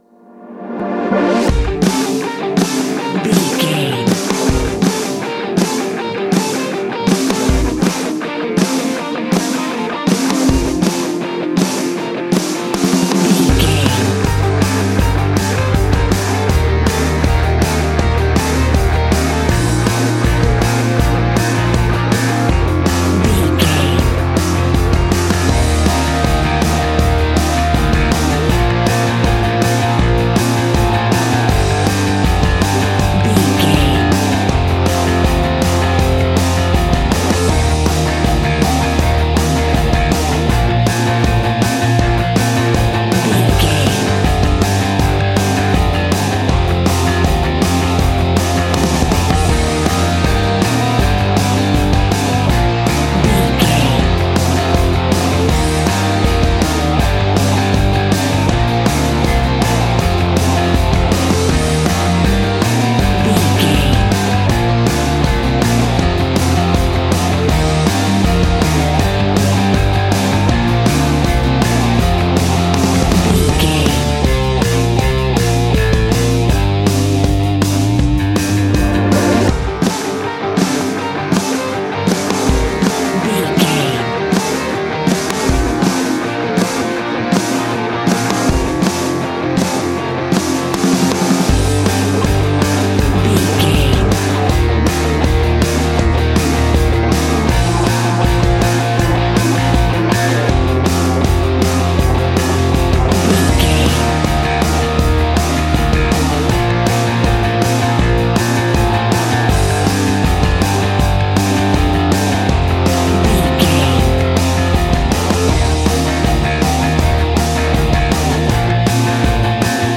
Aeolian/Minor
groovy
powerful
electric organ
drums
electric guitar
bass guitar